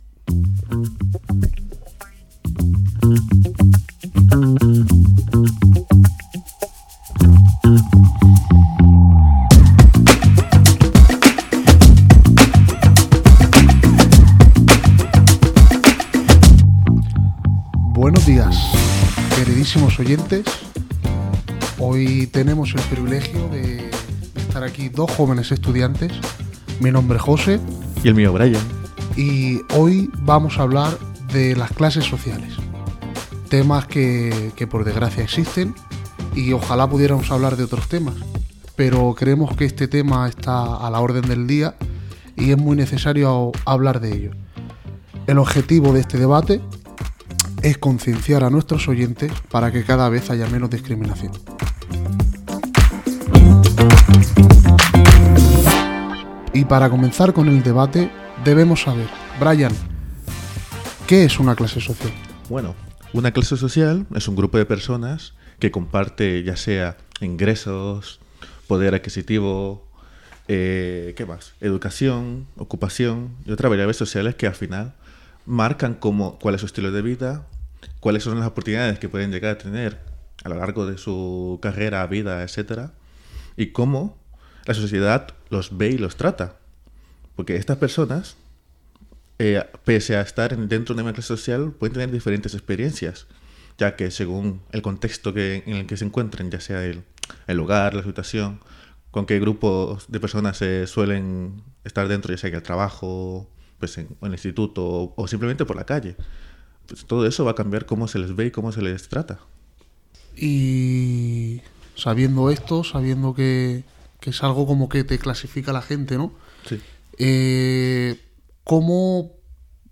Espai dedicat a les classes socials Gènere radiofònic Divulgació
Banda Internet